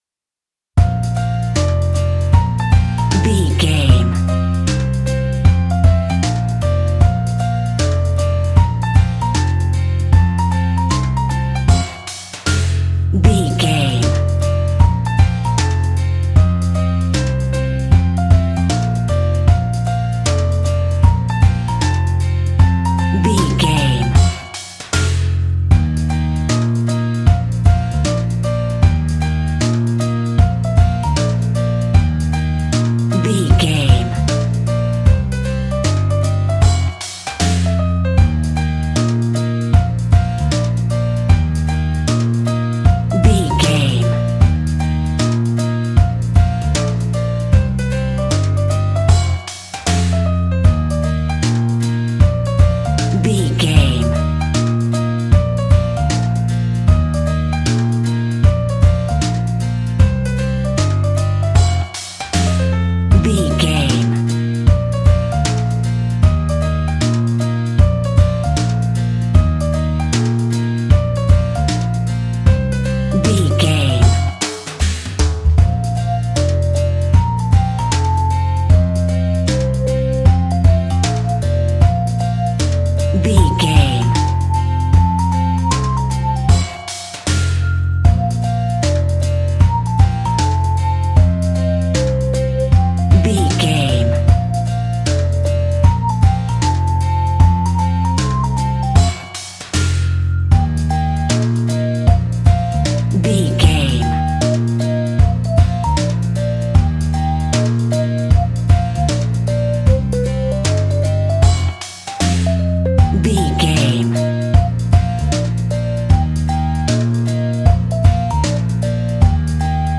Ionian/Major
B♭
childrens music
instrumentals
fun
childlike
happy
kids piano